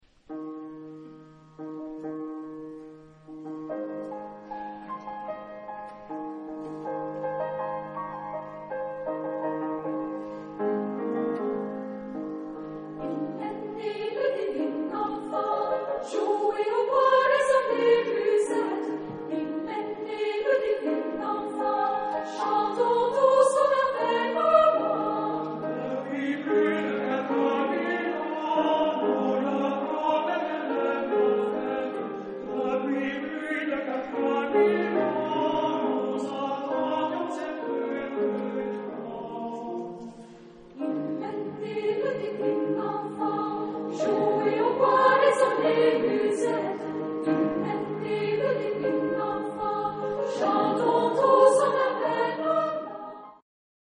SATB (4 voix mixtes) ; Partition condensée.
Chant de Noël.